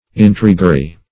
Intriguery \In*trigu"er*y\, n.